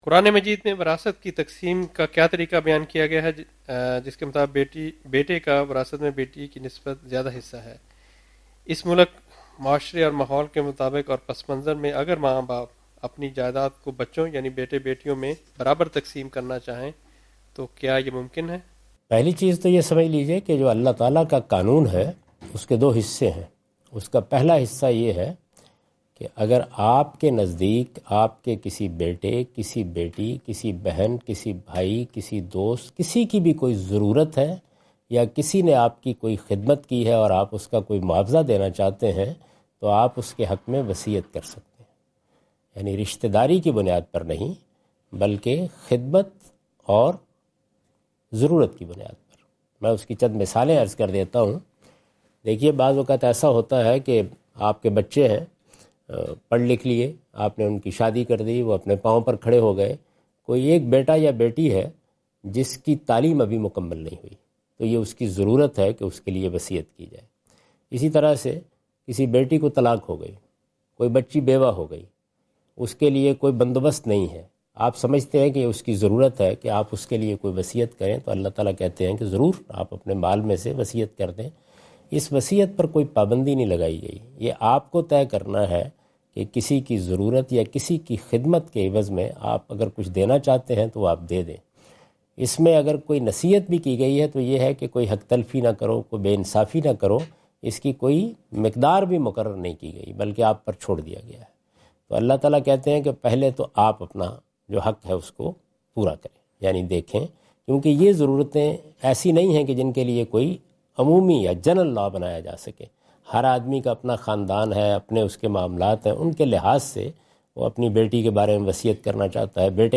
Javed Ahmad Ghamidi answer the question about "Islamic Law of Inheritance" during his Australia visit on 11th October 2015.
جاوید احمد غامدی اپنے دورہ آسٹریلیا کے دوران ایڈیلیڈ میں "اسلام کا قانونِ وراثت" سے متعلق ایک سوال کا جواب دے رہے ہیں۔